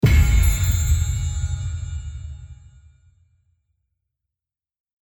bt_cha_levelup.mp3